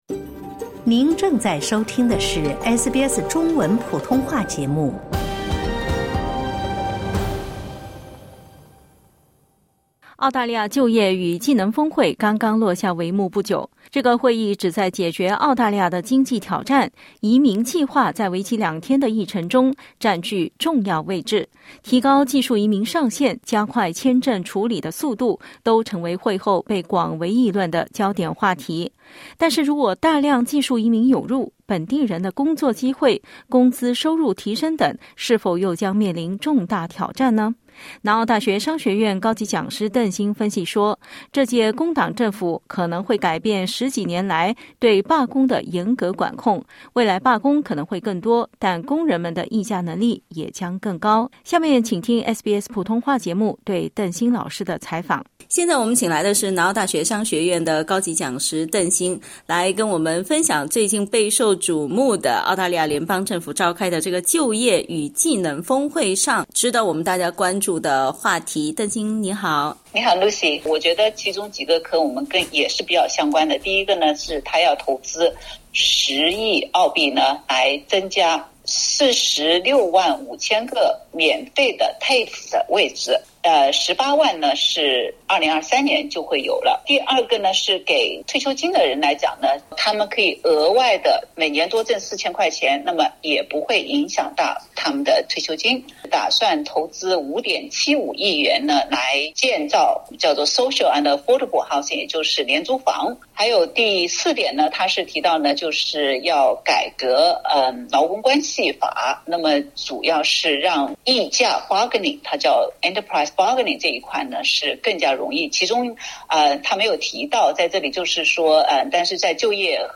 如果大量技术移民涌入，本地人的工作机会、工资收入提升等是否又将面临重大挑战？ (点击图片收听完整采访）